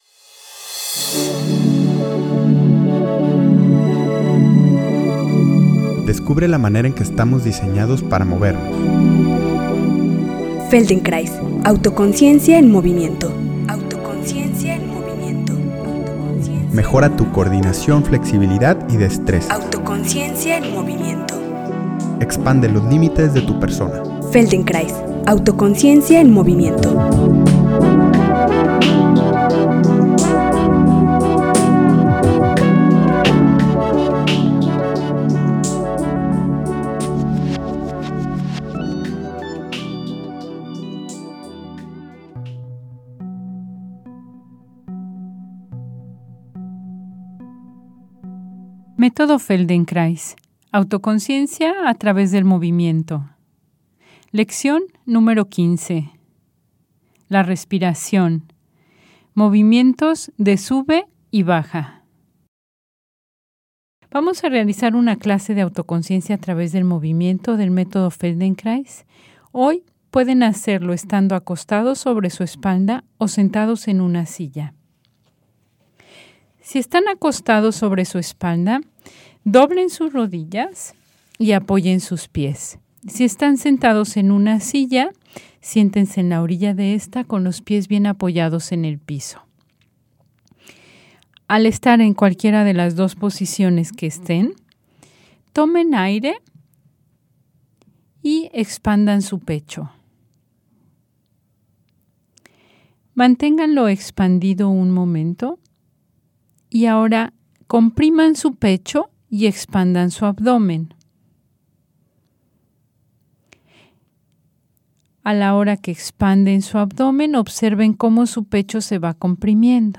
Dada la ansiedad que pueda generar la situación del coronavirus, les envío esta lección del Método Feldenkrais que puede ayudar a disminuirla y crear una reorganización de la corporeidad.